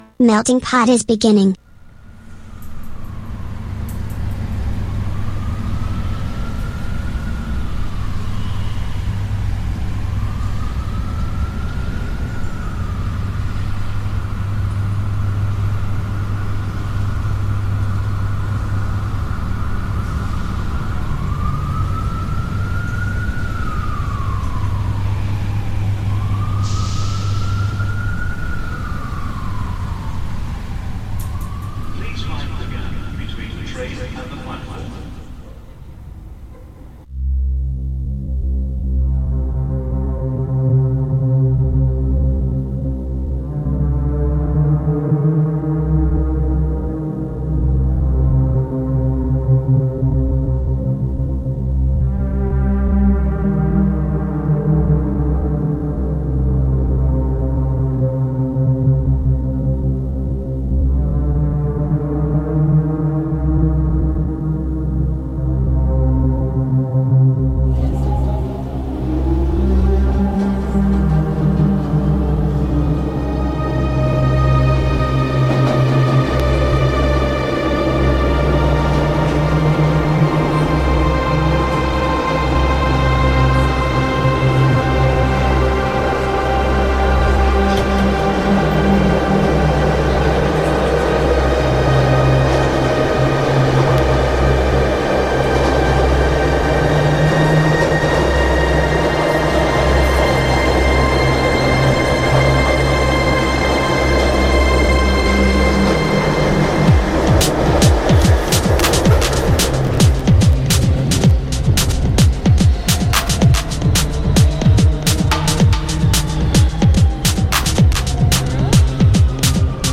MUSICA NOTIZIE INTERVISTE A MELTINGPOT | Radio Città Aperta